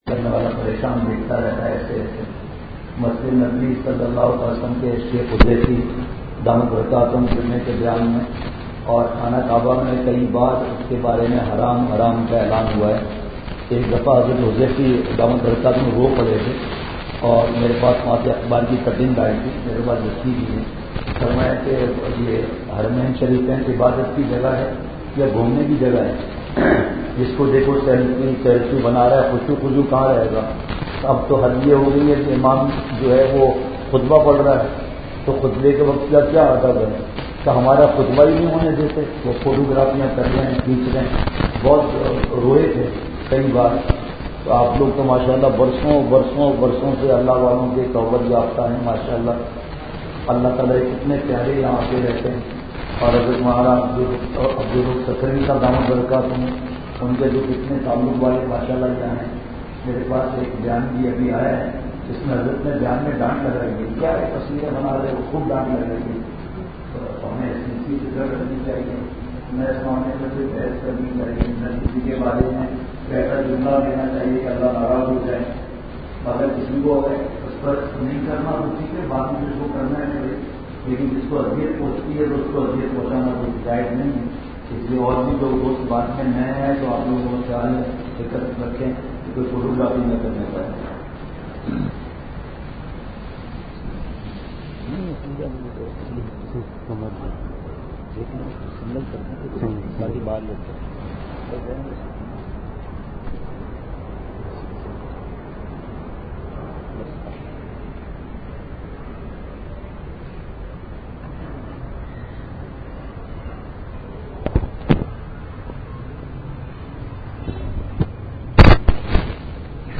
Safar Sa After Magrib 11 Dec 2018 Masjid Darul Uloom Azaadville May Dard Bahra Bayan